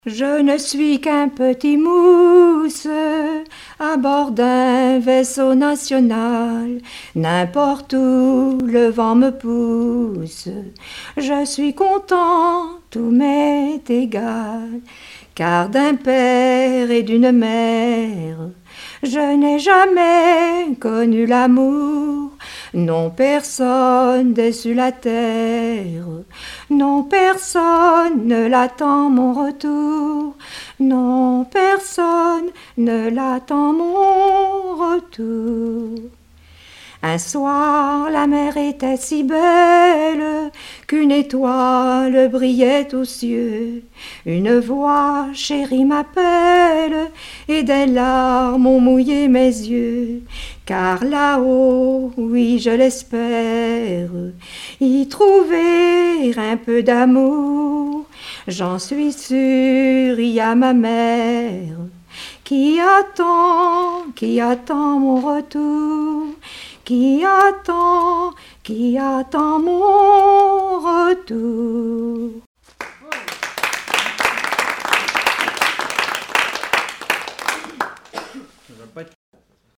Collectif de chanteurs du canton - veillée (2ème prise de son)
Pièce musicale inédite